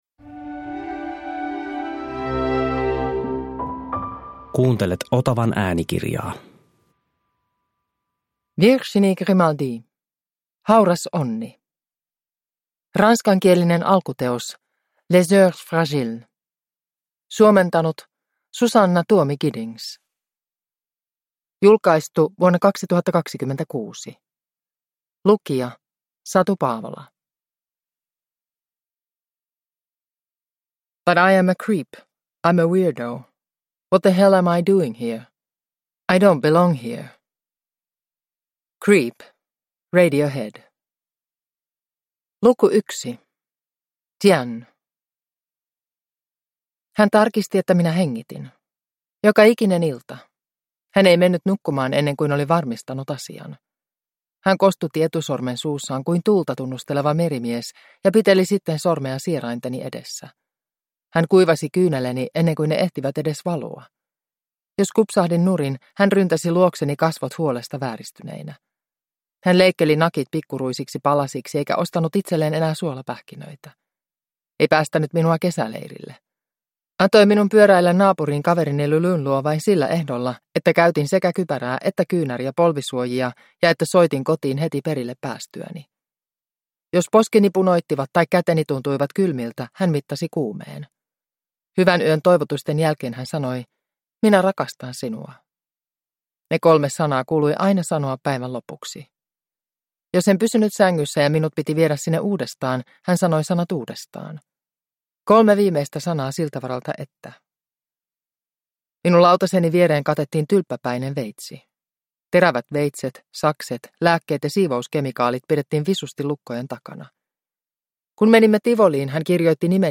Hauras onni (ljudbok) av Virginie Grimaldi